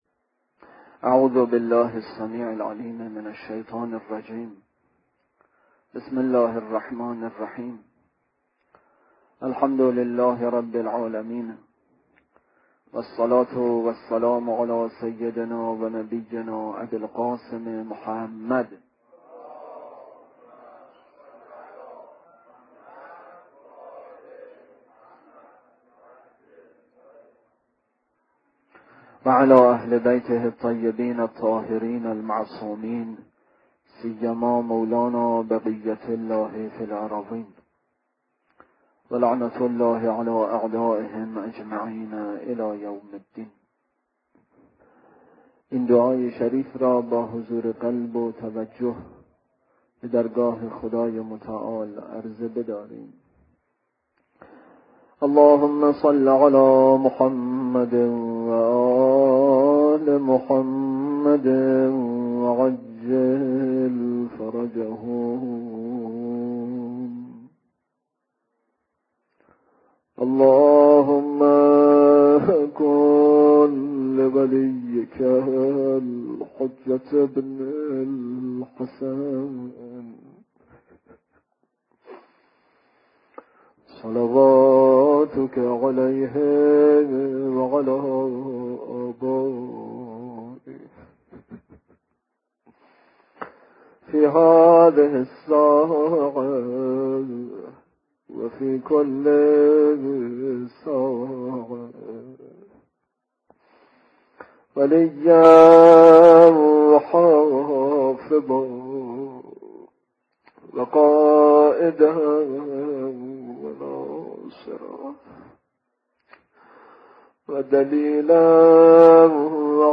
روضه: روضه‌ی عطش، روضه‌ی حضرت علی اصغر(ع) مرورگر شما فایل صوتی را پشتیبانی نمی کند.